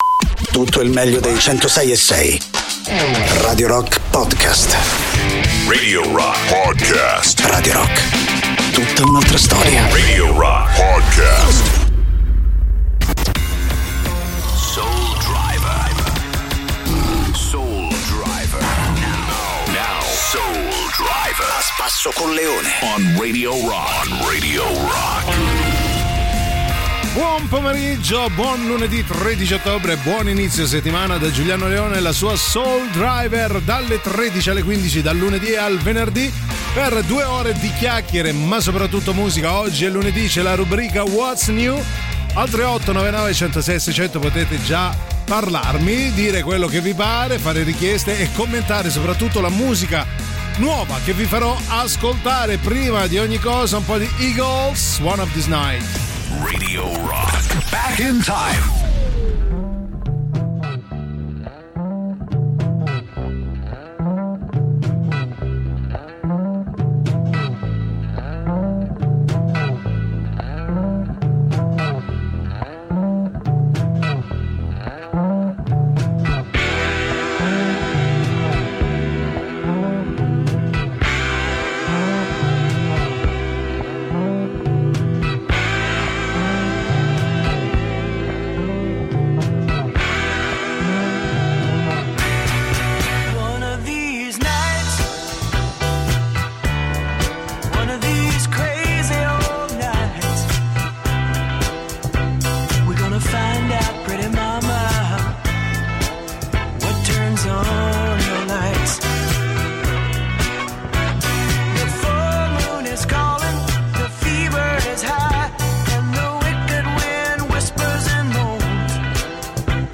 in diretta dal lunedì al venerdì, dalle 13 alle 15